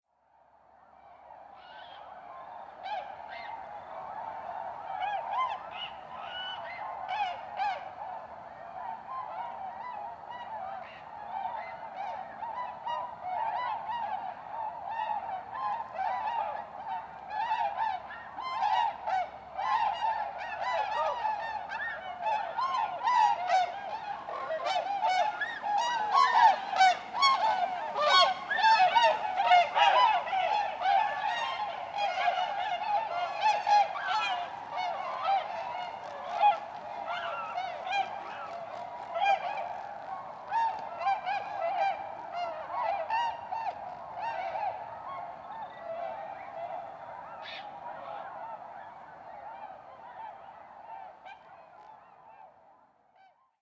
Звуки перелётных птиц
Звук весенних стай лебедей, возвращающихся с юга